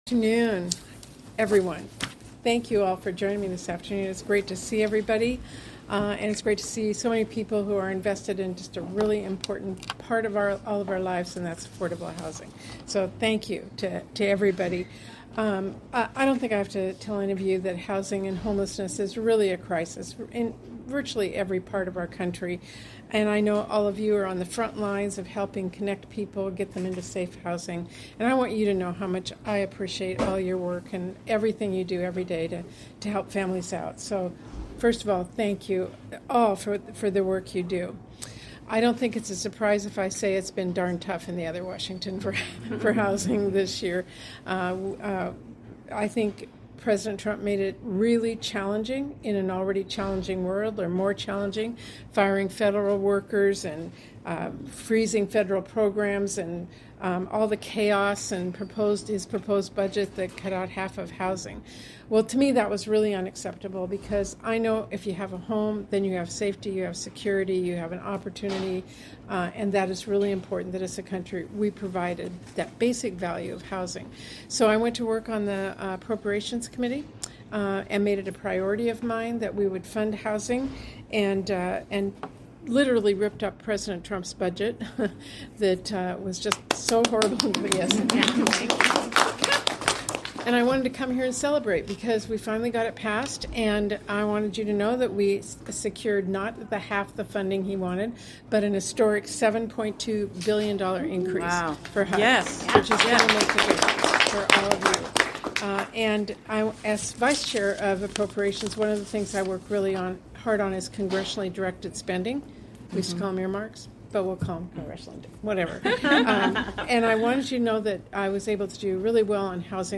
***AUDIO OF ROUNDTABLE HERE
Seattle, WA — Today, U.S. Senator Patty Murray (D-WA), Vice Chair of the Senate Appropriations Committee, held a roundtable discussion with local housing providers and partners on the importance of lowering the cost of housing and the urgent need to address the homelessness crisis.